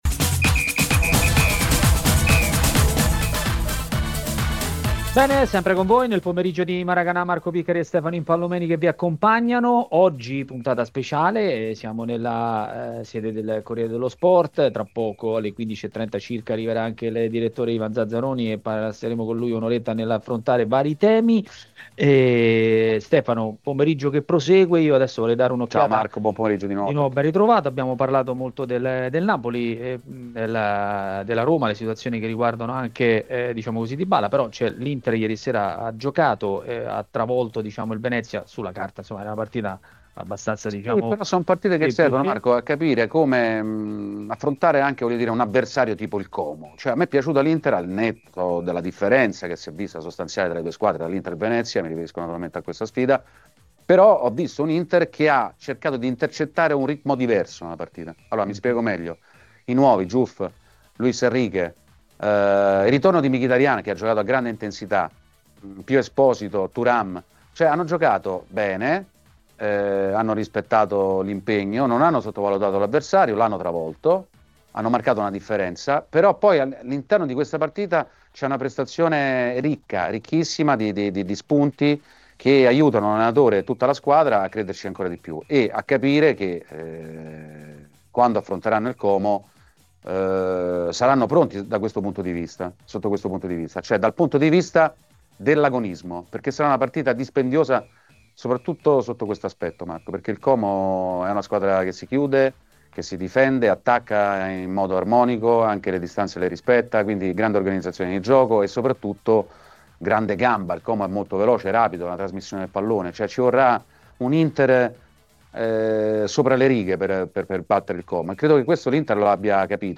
Notizie